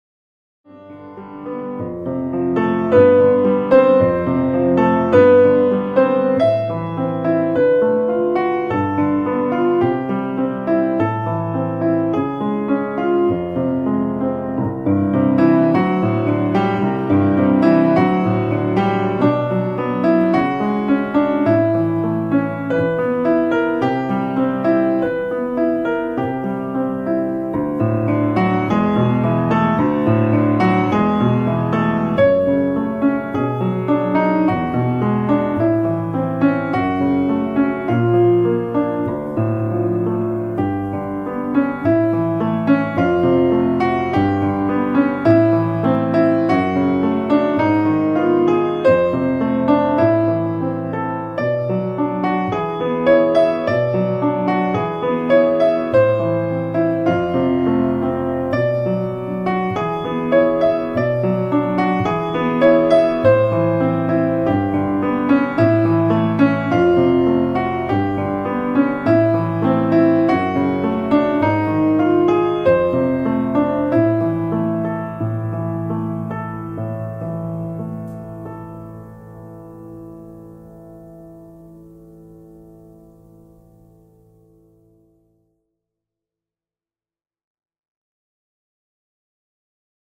Flügelaufnahme solo Kammfiltereffekt minimieren